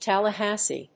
/ˌtælʌˈhæsi(米国英語), ˌtælʌˈhæsi:(英国英語)/